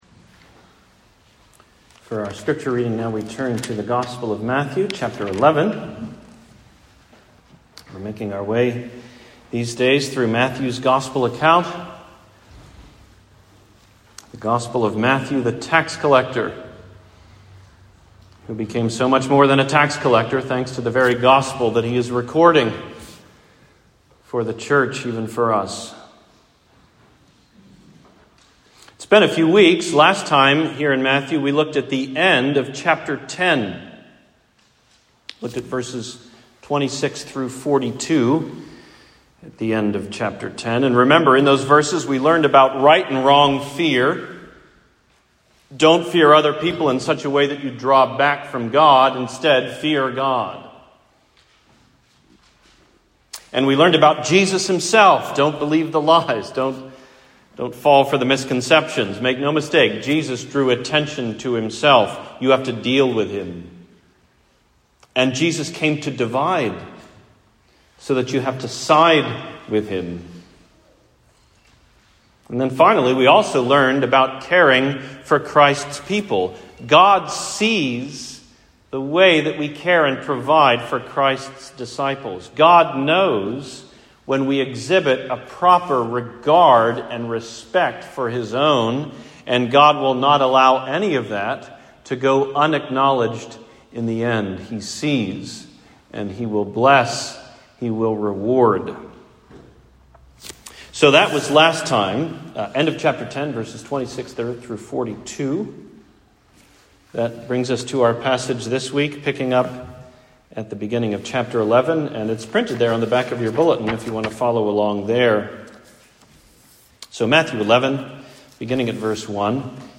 An Answer for John the Baptist: Sermon on Matthew 11:1-19